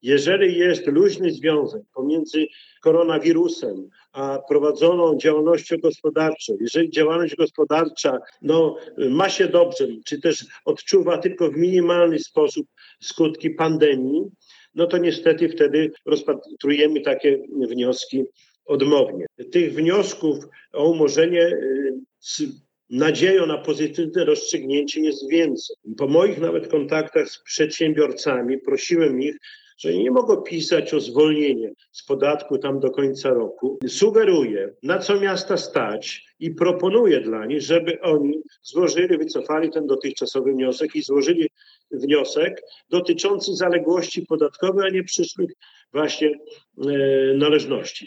– Wszystkie prośby zostały rozpatrzone pozytywnie – poinformował na ostatniej sesji Rady Miejskiej Czesław Renkiewicz, prezydent miasta.